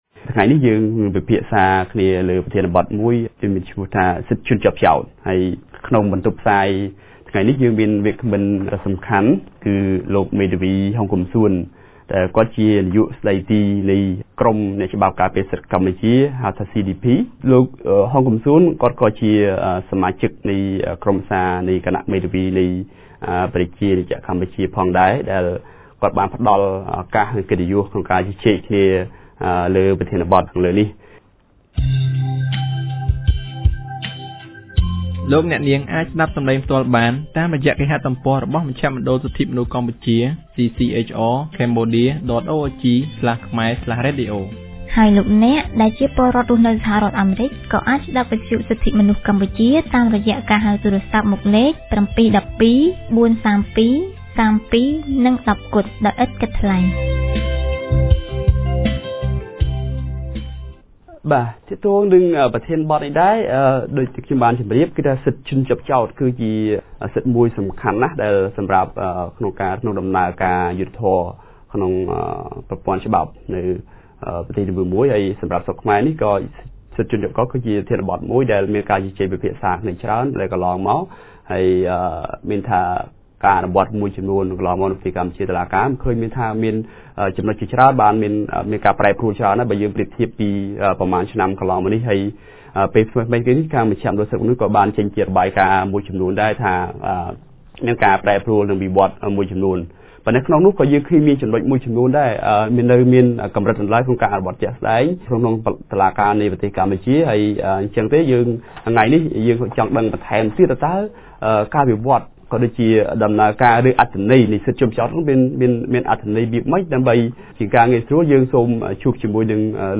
On 17 December 2014, CCHR TMP held a radio about Defendant Rights which is the most important part of Fair Trial Rights.